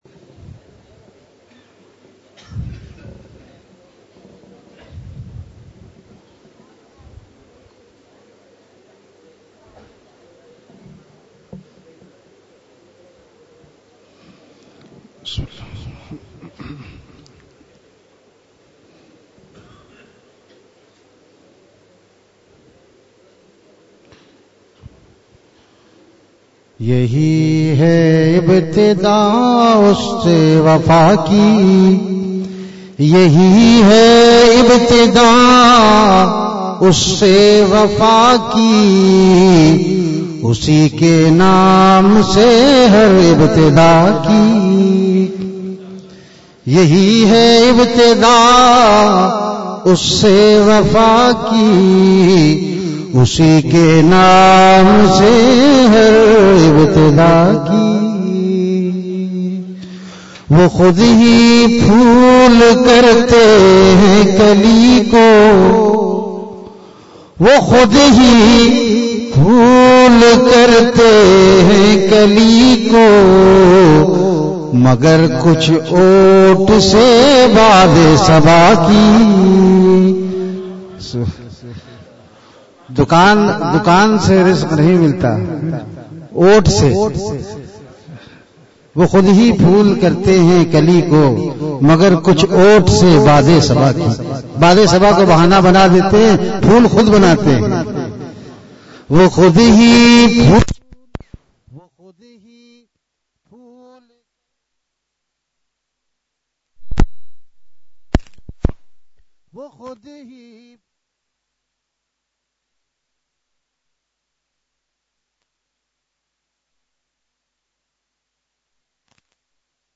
Basm e Ashaar e at the Majlis